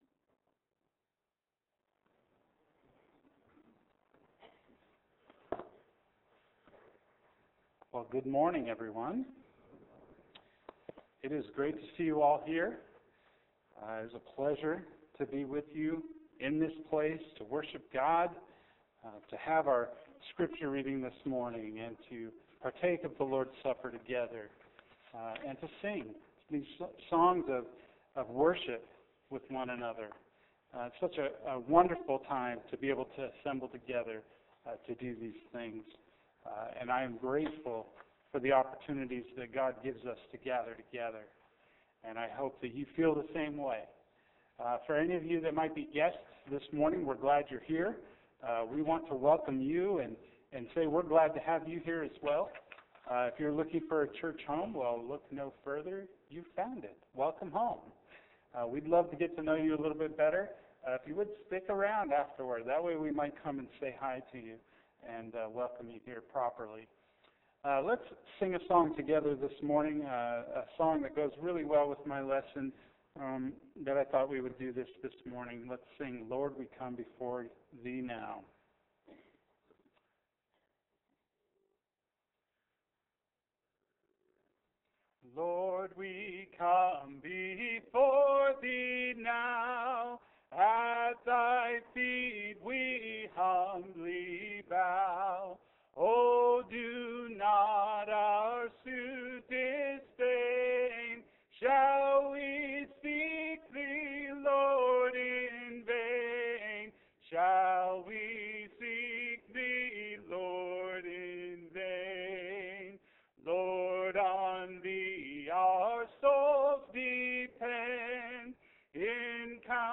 The Sign of the Cross – John 19:17-22 – Sermon — Midtown Church of Christ